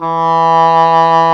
WND OBOE E3.wav